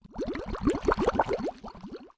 Acid_Bubble.mp3